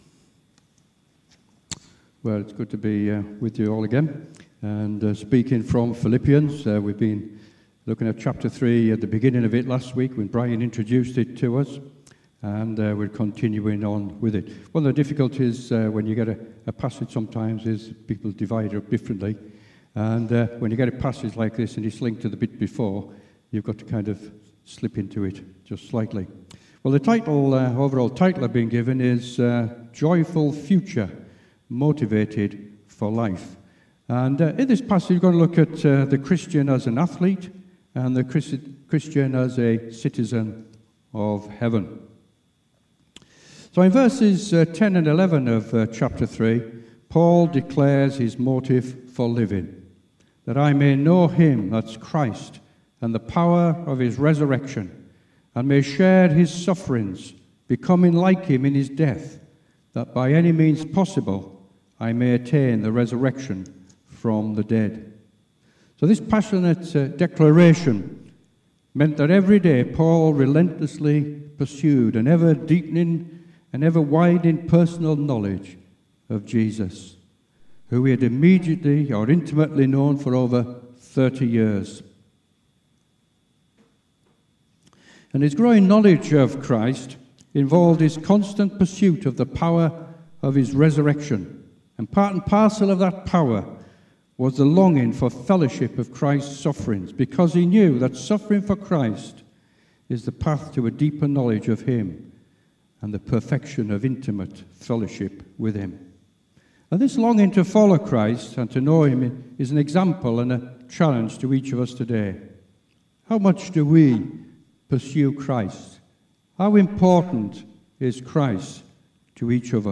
A message from the series "Philippians."